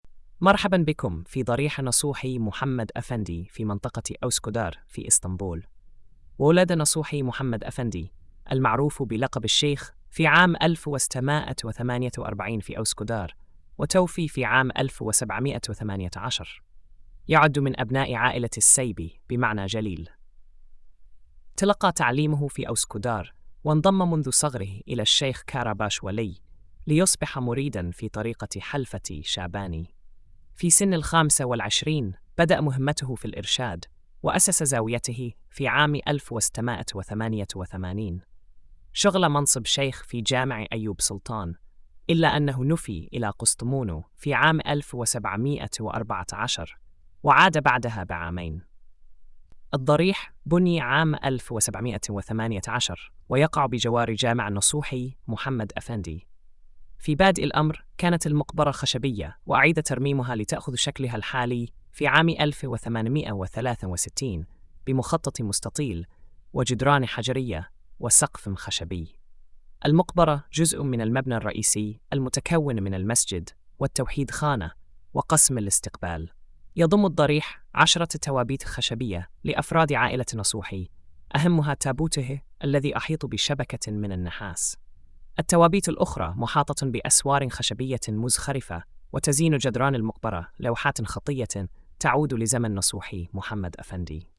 السرد الصوت: